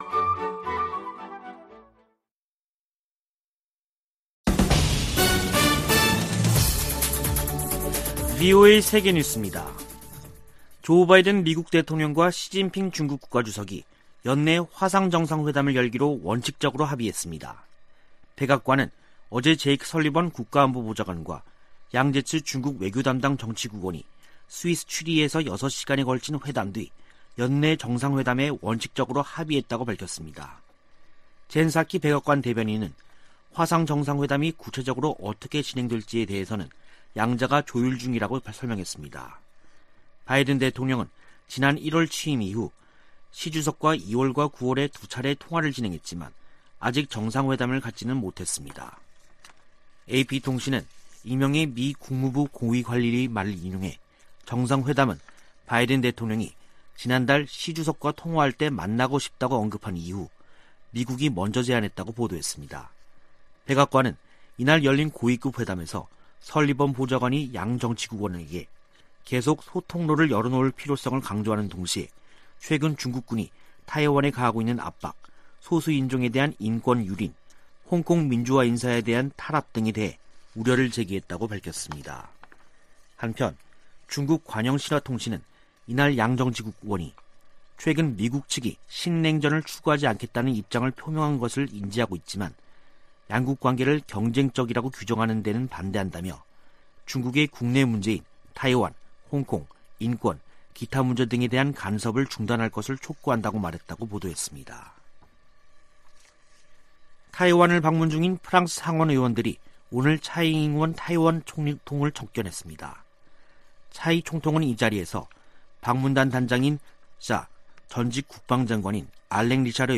VOA 한국어 간판 뉴스 프로그램 '뉴스 투데이', 2021년 10월 7일 2부 방송입니다. 북한이 영변 핵시설 내 우라늄 농축공장 확장 공사를 계속하고 있는 것으로 파악됐습니다.